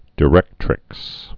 (dĭ-rĕktrĭks, dī-)